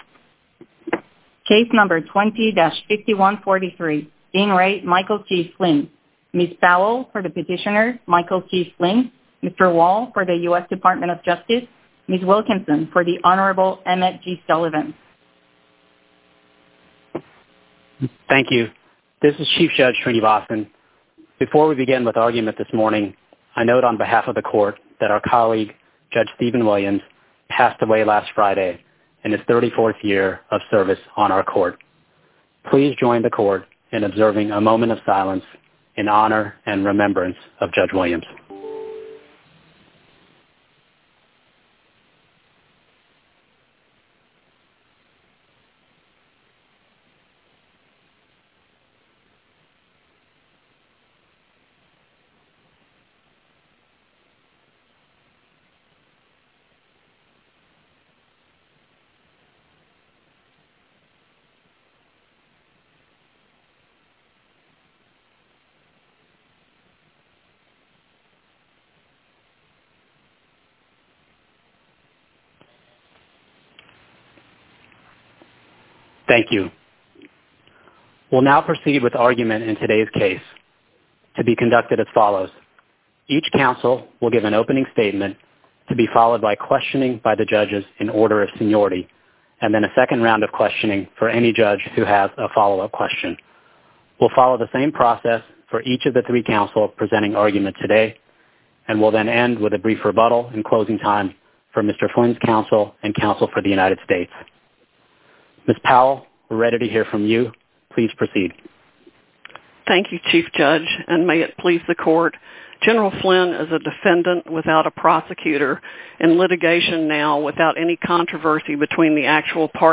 USCA-DC Oral Argument Recordings